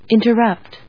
音節in・ter・rupt 発音記号・読み方
/ìnṭərˈʌpt(米国英語), ˌɪntɜ:ˈʌpt(英国英語)/